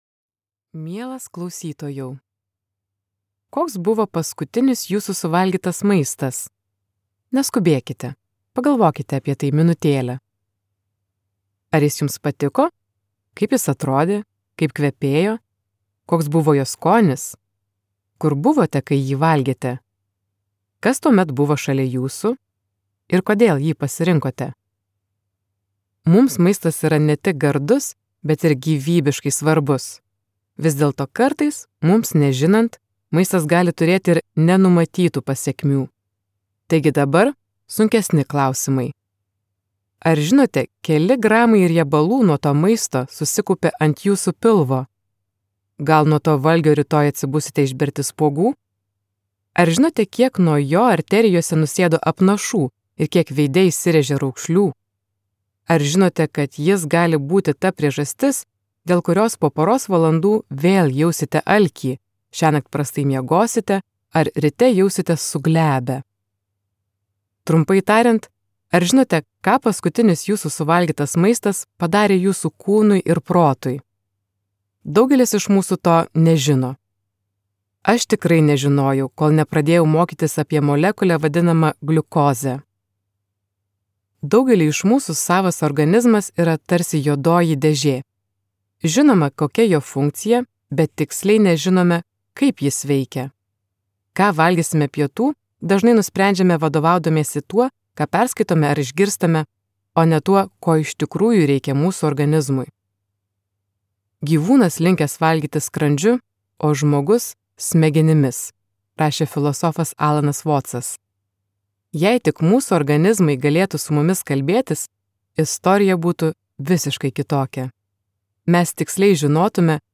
Gliukozės revoliucija | Audioknygos | baltos lankos